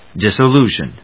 音節dis・so・lu・tion 発音記号・読み方
/dìsəlúːʃən(米国英語), ˌdɪsʌˈlu:ʃʌn(英国英語)/